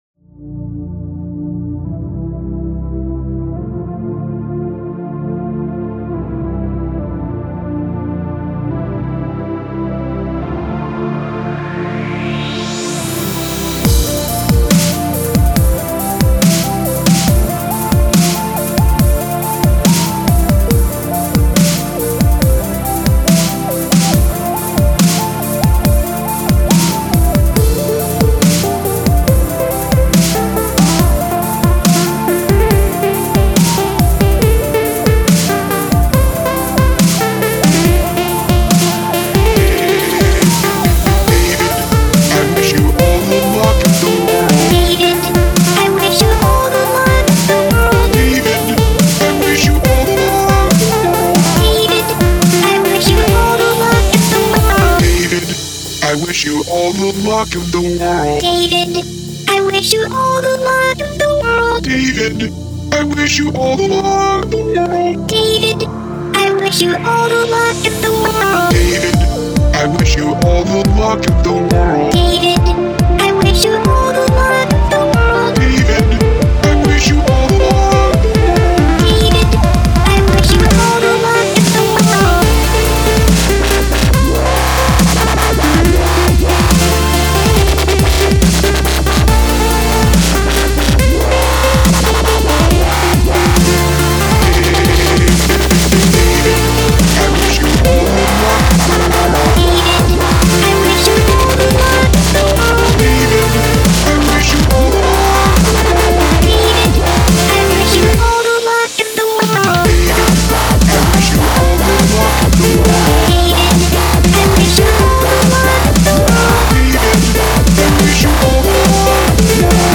DUB STEP--> [6]
drum step